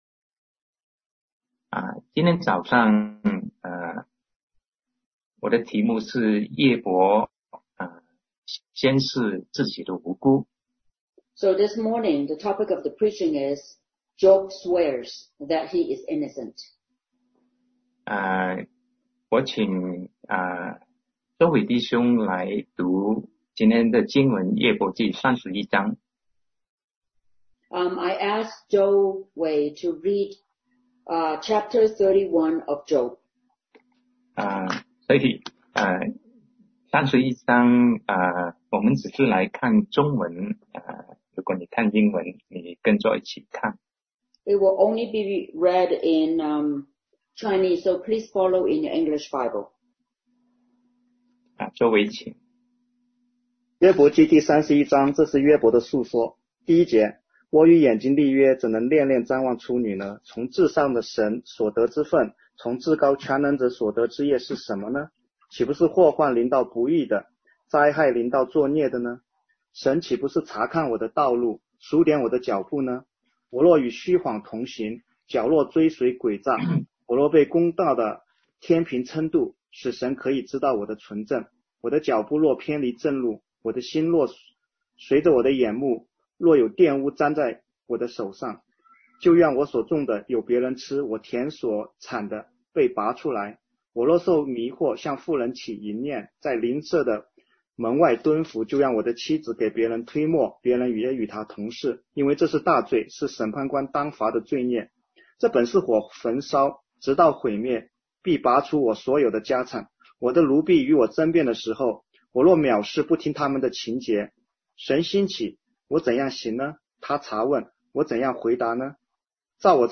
Sermon 2020-06-28 Job Swears that He is Innocent